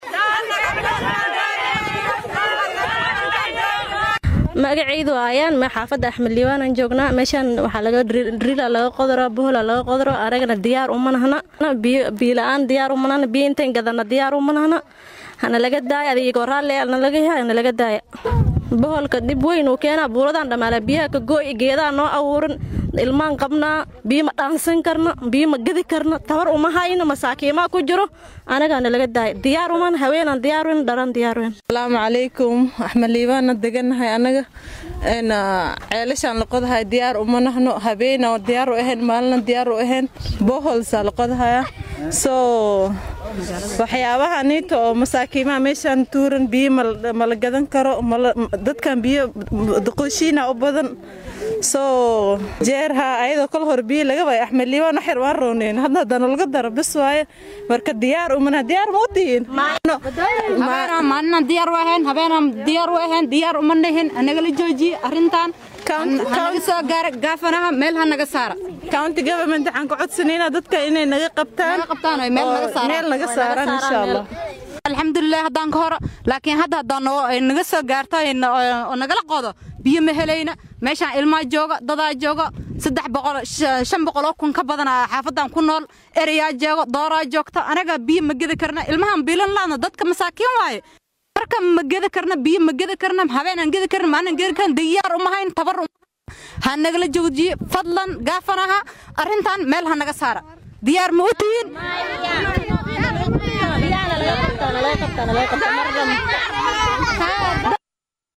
DHEGEYSO:Hooyooyiinka Xaafad ka mid ah Wajeer oo cabasho muujinaya